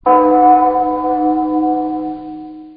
Gong For Bell Sound Effect Free Download
Gong For Bell